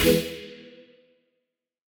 FR_T-PAD[hit]-E.wav